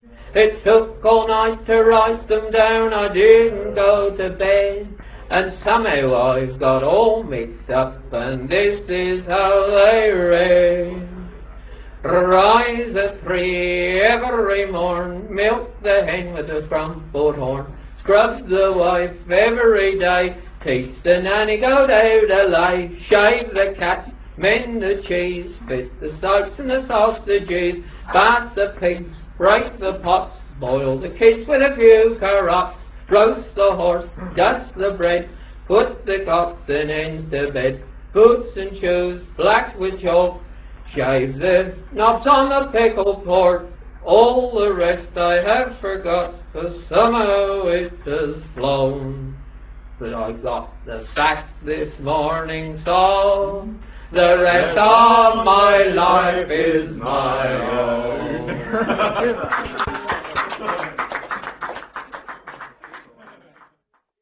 Songs from Country Pubs - Various
These are live pub recordings made on a domestic tape recorder so inevitably there is some background noise and the singers are not always accorded the best of order.